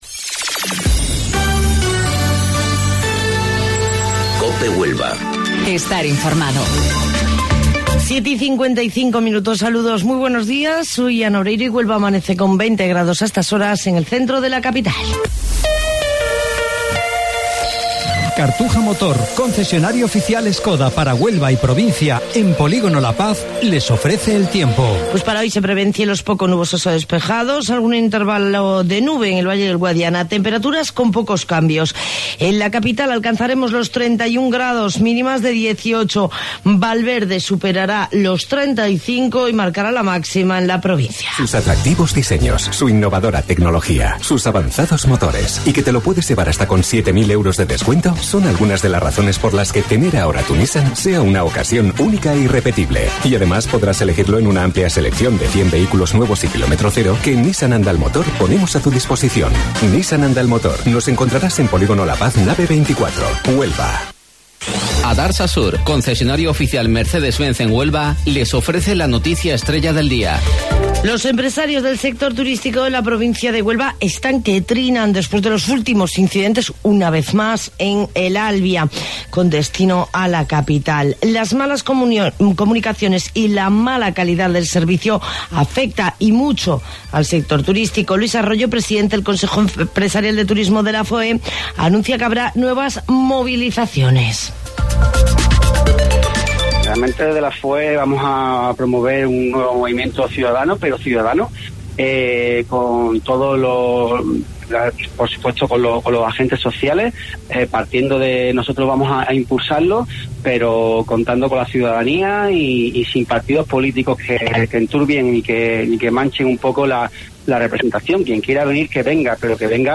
AUDIO: Informativo Local 07:55 del 16 de Julio